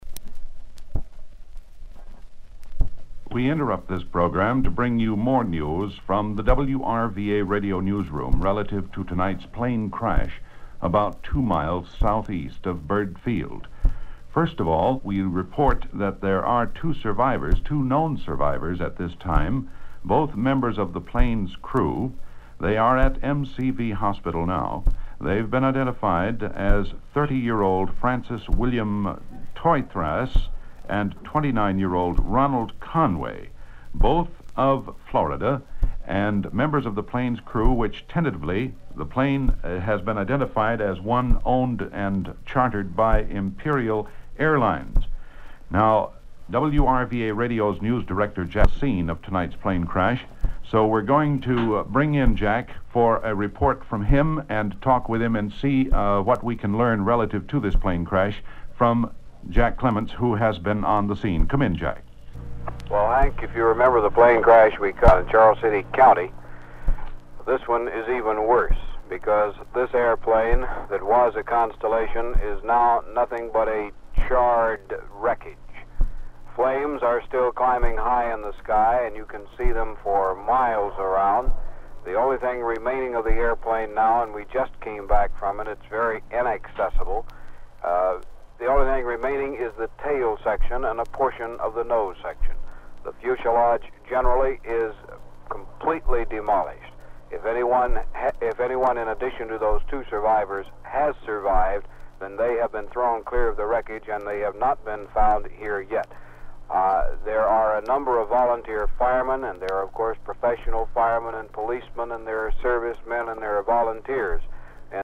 These sound recordings, consisting of electrical transcriptions, acetate disks, and reel to reel tapes, contain a wide array of broadcasting from the 1930's to the 1990's. The clips below represent only a small portion of the sound recordings.
News
Imperial_Plane_Crash.mp3